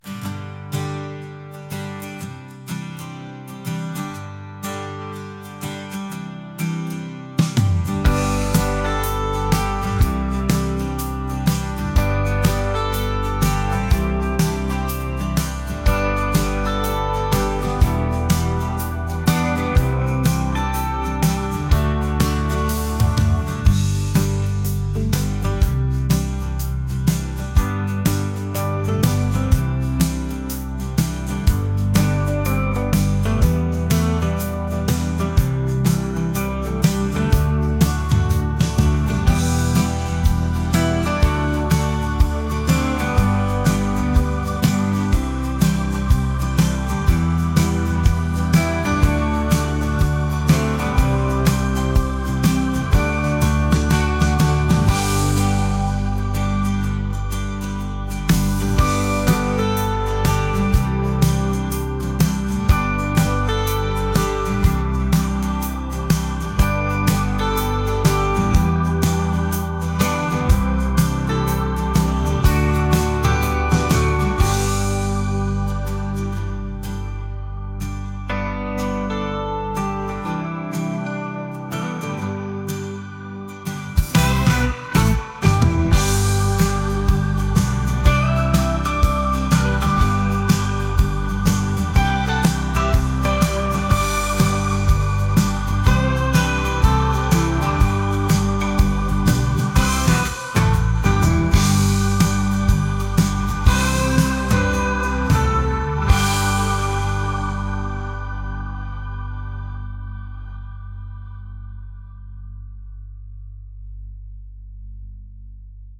acoustic | pop | lofi & chill beats